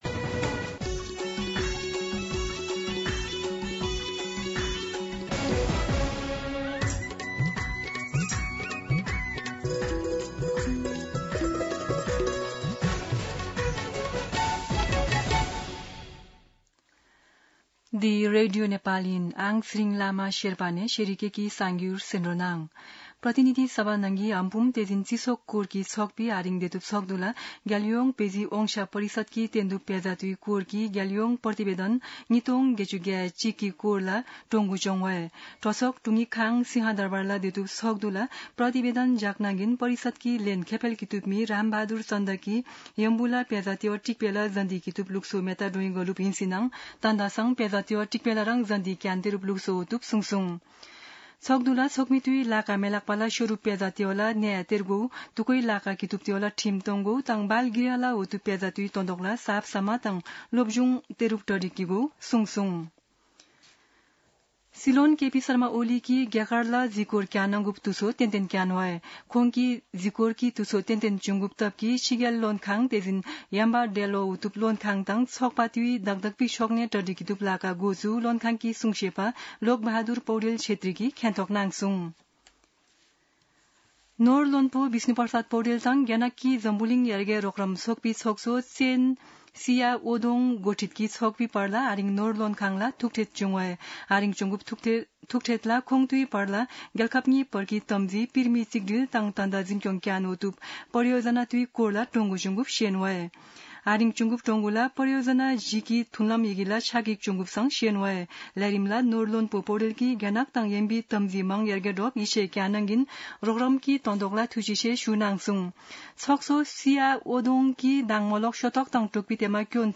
An online outlet of Nepal's national radio broadcaster
शेर्पा भाषाको समाचार : ५ साउन , २०८२